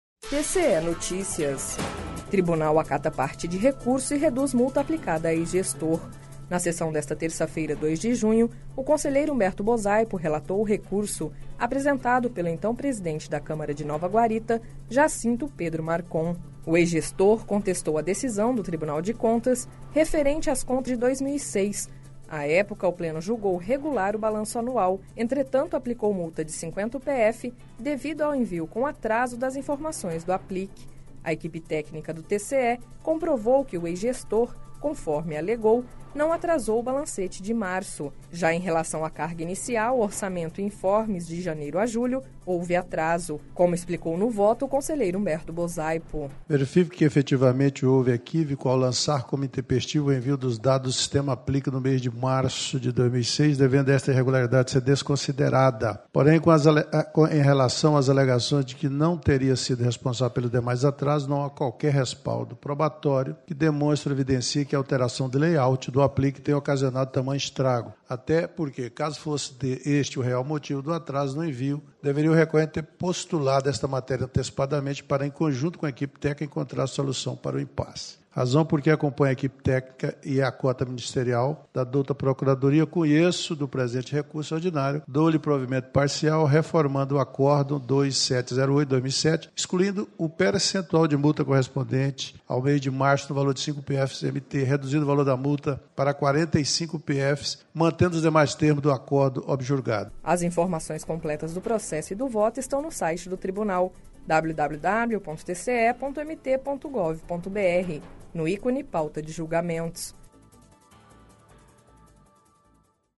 Sonora: Humberto Bosaipo - conselheiro do TCE-MT